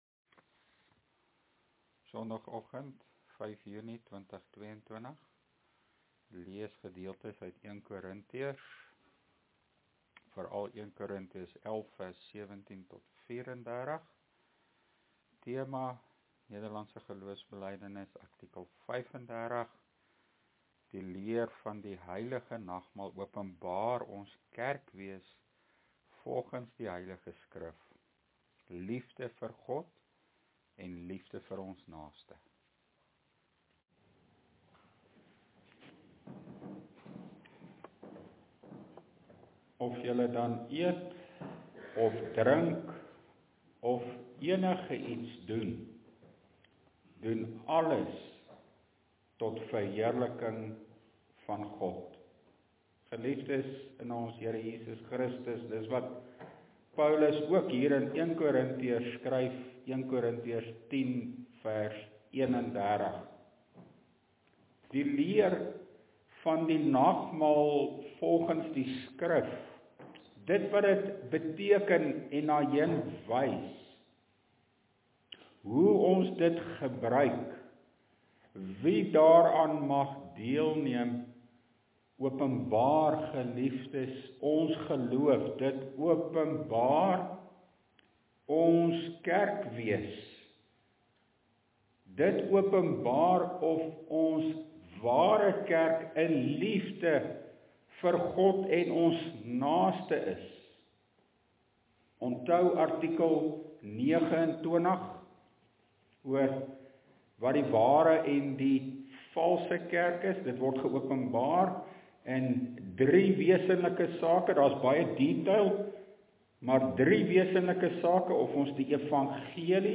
LEER PREDIKING: NGB artikel 35 – Die heilige nagmaal (1 Kor. 11:17-34)
Tema: Die heilige nagmaal openbaar ons kerkwees en die erns wat ons daarmee maak (Preekopname: GK Carletonville, 2022-06-03 , nota: let wel, die inhoud van die preek en teksnotas hier onder stem nie altyd ooreen nie, die notas is nie volledig nie, die audio preek is die volledige preek).